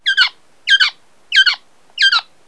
Quail.wav